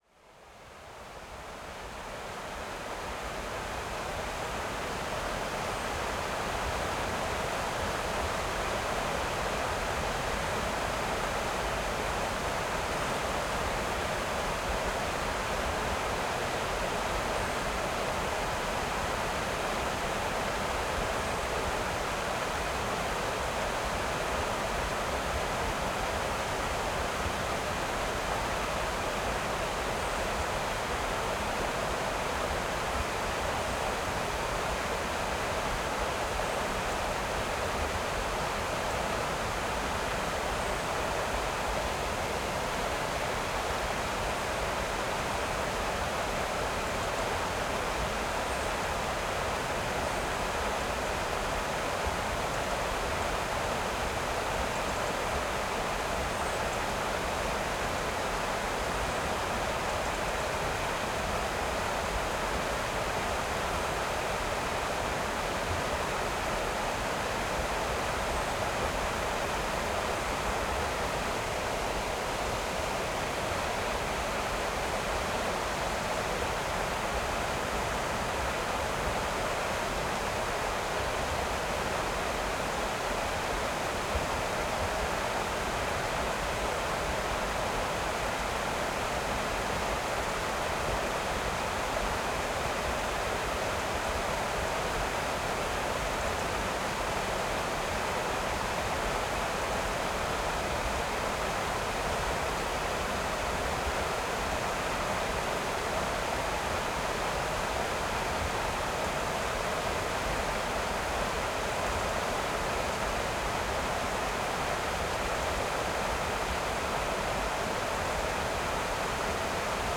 Summer_Mountains_Stream_Big_Forest_Distant_Perspective.ogg